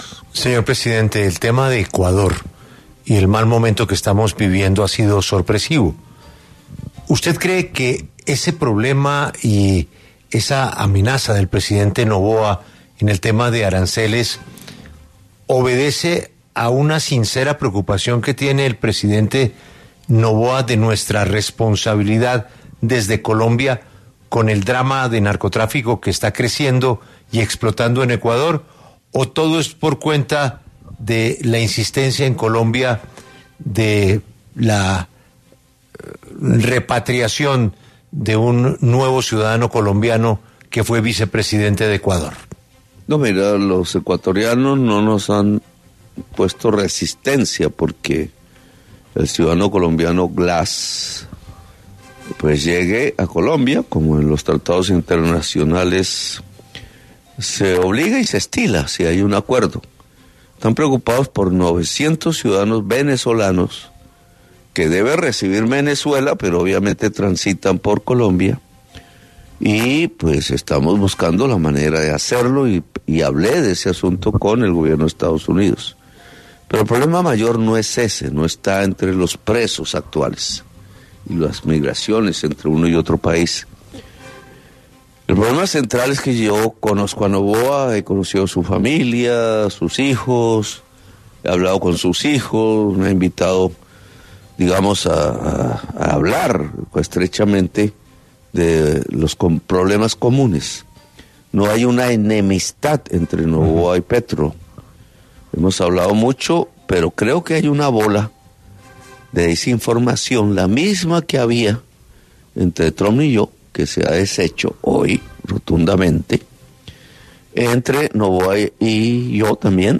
El presidente Gustavo Petro habló en exclusiva para los micrófonos de Caracol Radio, con Julio Sánchez Cristo, este martes, 3 de febrero, y allí reveló que durante la reunión con su homólogo de Estados Unidos, Donald Trump, este mismo le confirmó que será mediador entre Colombia y Ecuador tras la tensión diplomática que hay entre ambas naciones.